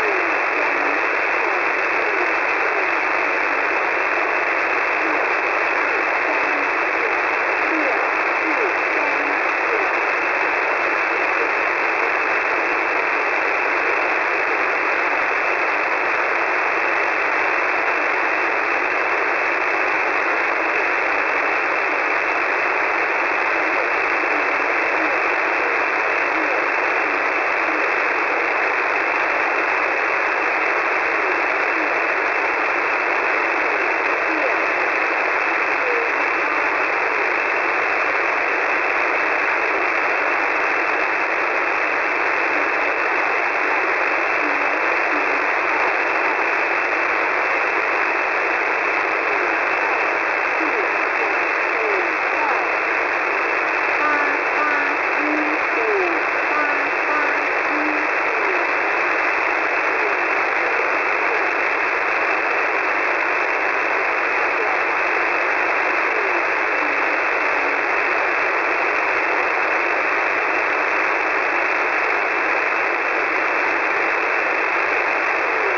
Mode: AM
Comments: very faint, hard to hear